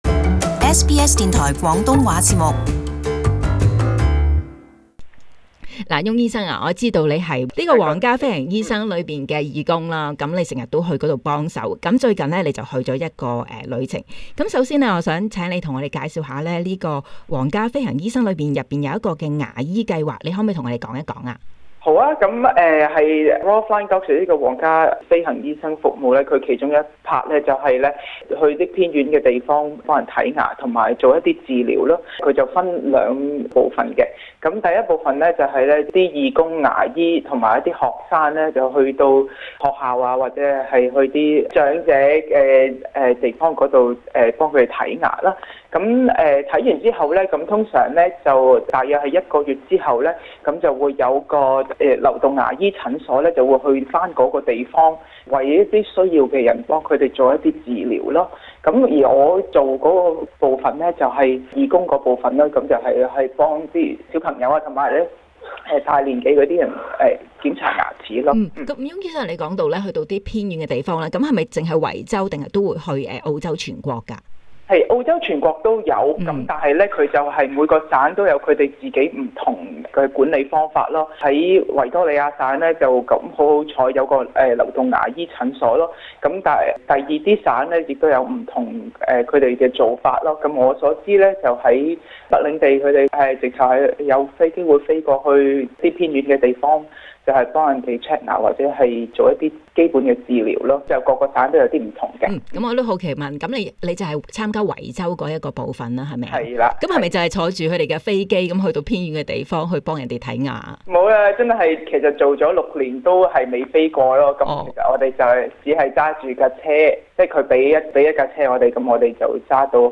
【社区专访】皇家飞行医生牙医服务